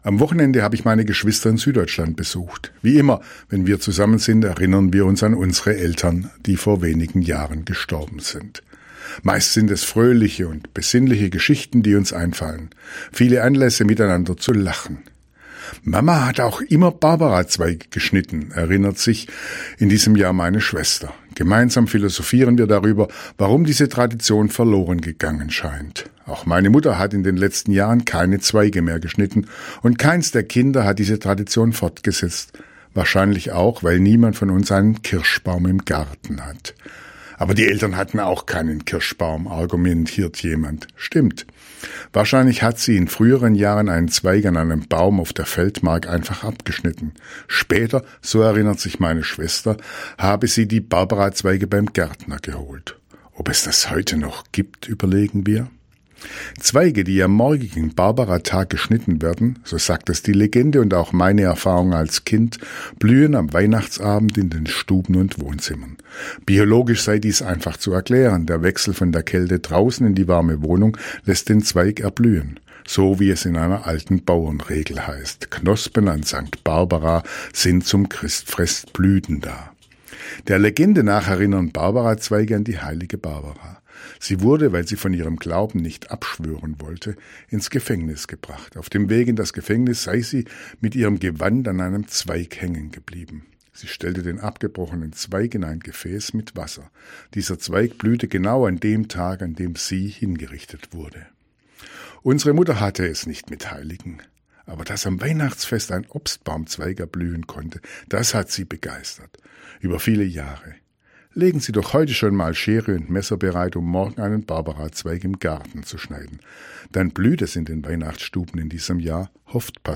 Radioandacht vom 3. Dezember